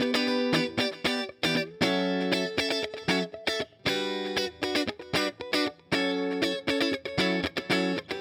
11 Rhythm Guitar PT3.wav